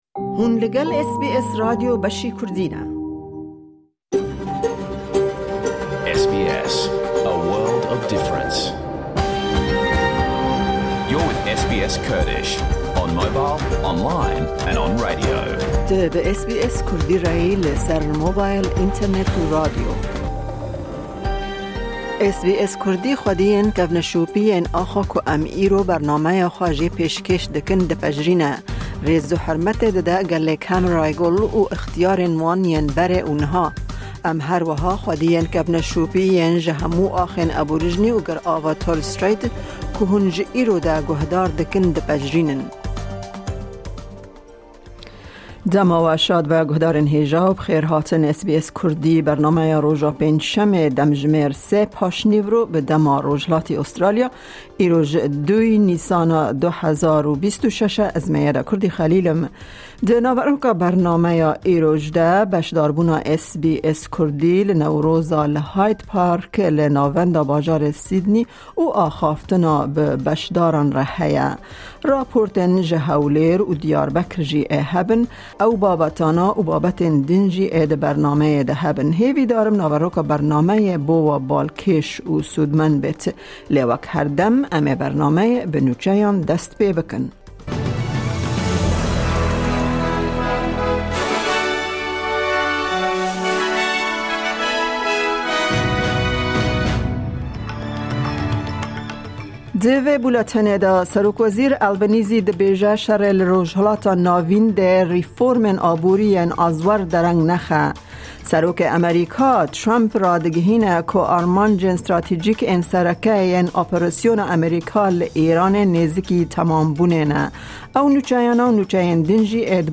Nûçe, raportên ji Hewlêr û Amedê, gotinên guhdaran derbarê Newrozê û gelek babetên di yên cur bi cur di naveroka bernameyê de tên dîtin.